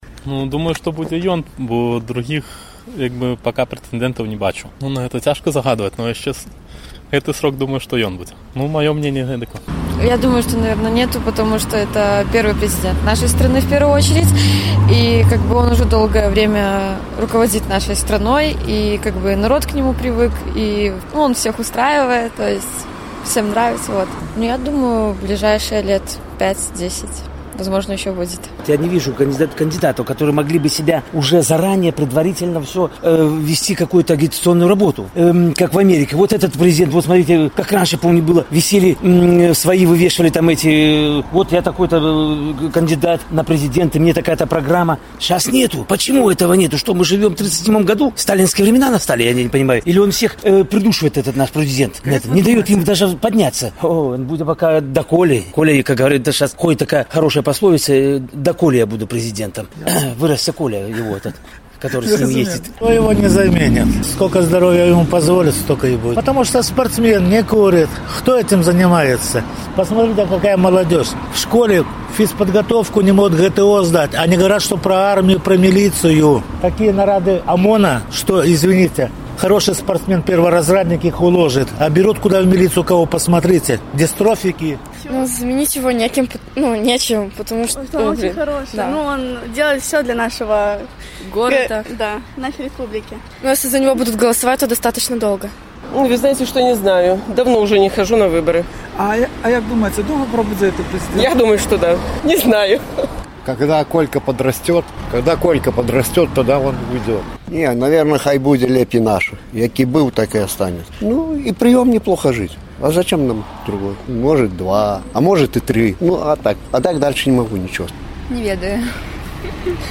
Як доўга будзе кіраваць Беларусьсю Лукашэнка і хто яго заменіць? З такім пытаньнем наш карэспандэнт зьвяртаўся да гарадзенцаў.